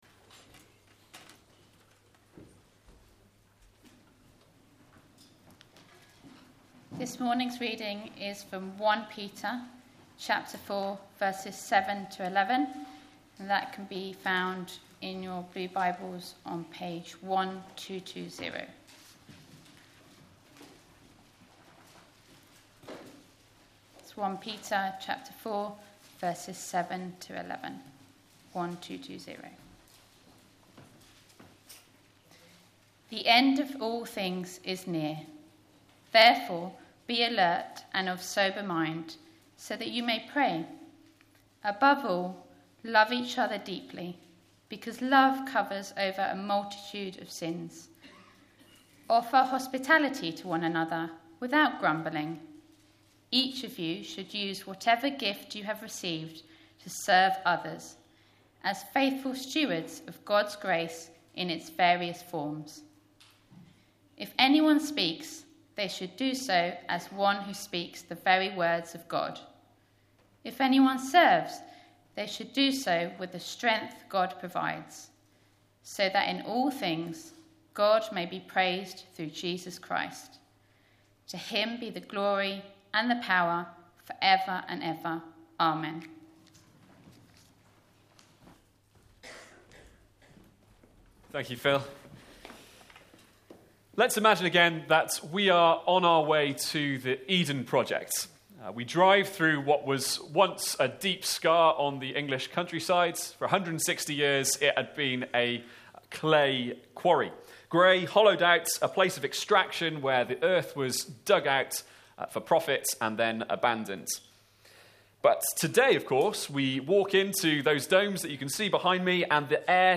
Sermons | ChristChurch Banstead